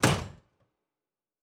Fantasy Interface Sounds
UI Tight 17.wav